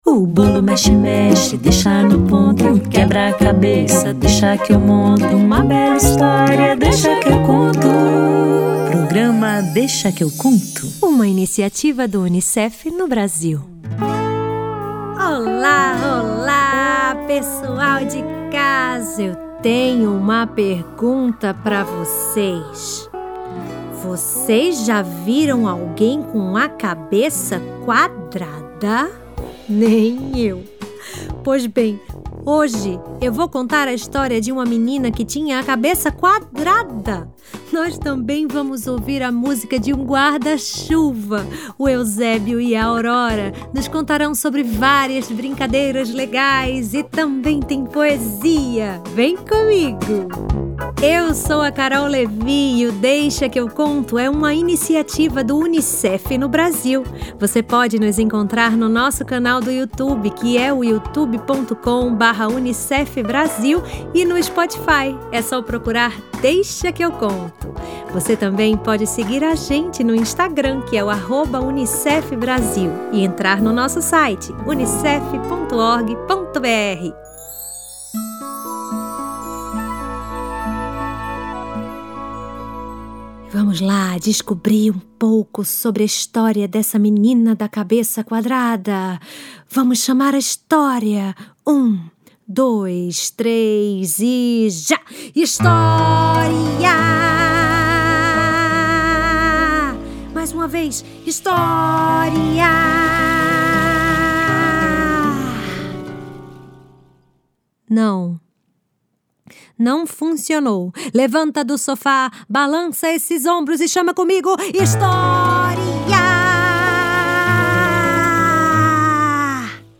Também tem música, brincadeiras com Euzébio e Aurora e poesia!